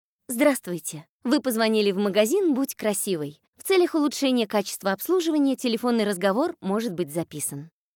Примеры голосовых приветствий
Bud_krasivoy_golosovoe_privetstvie.mp3